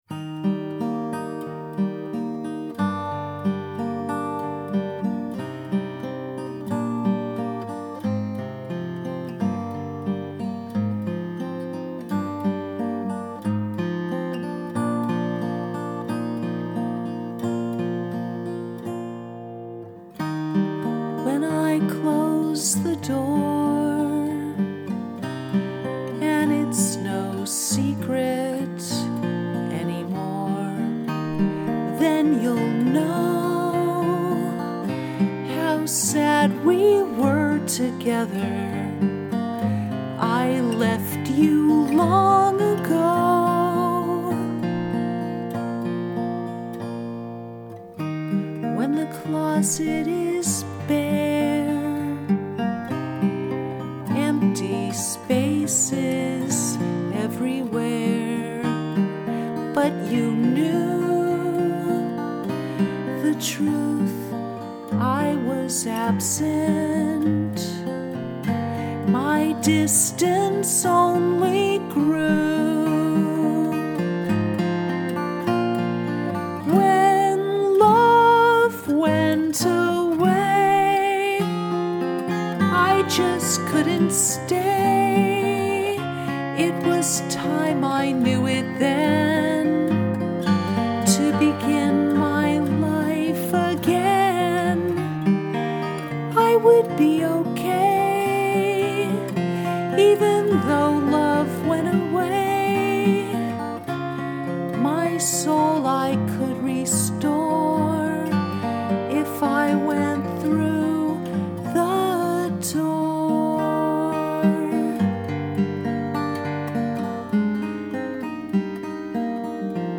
The Door Acoustic Home Recording – 5/10/16